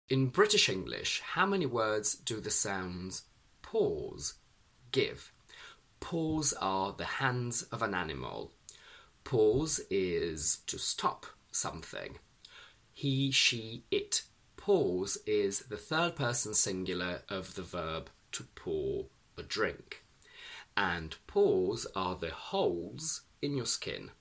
/pɔ:z/ How any and sound effects free download
How any and Mp3 Sound Effect /pɔ:z/ - How any and what words do these sounds give in British English?